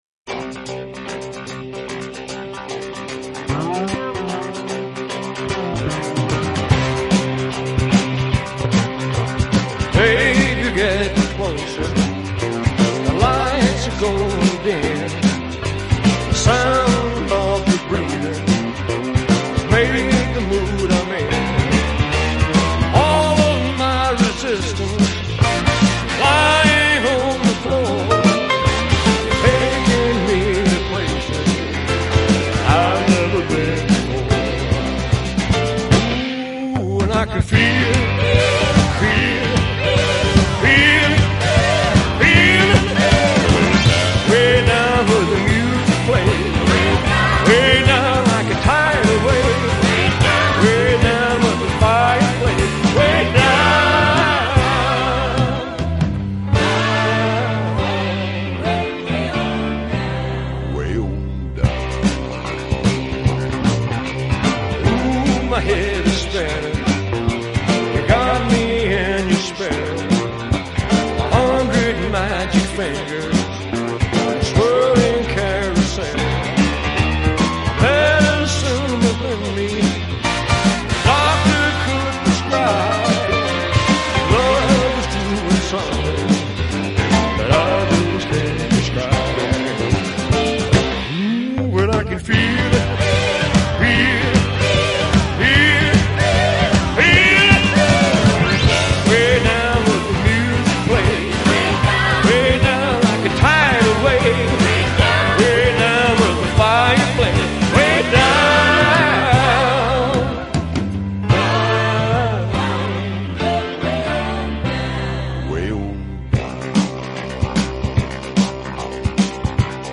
Второй дубль